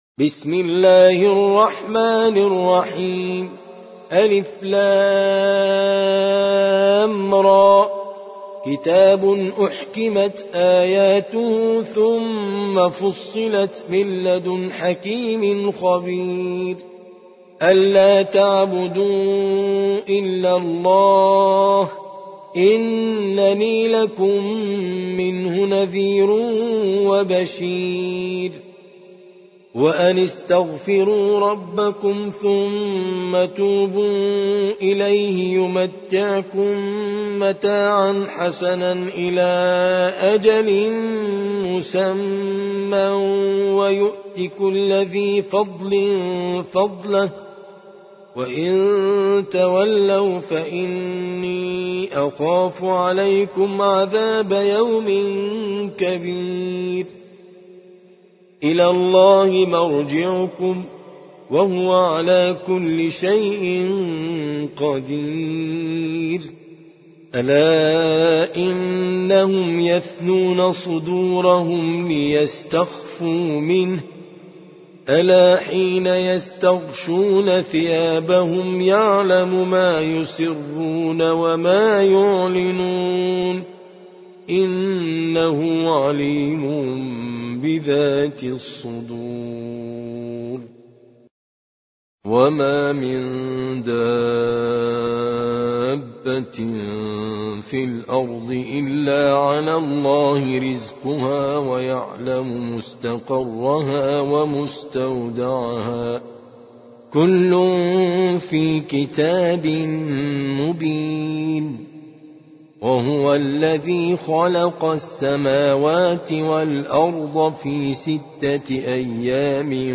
11. سورة هود / القارئ